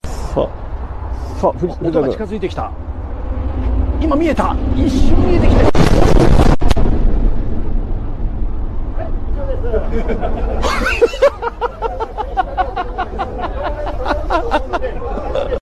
Japanese people watch a new bullet train go by at 500 Kmh (310 MPH)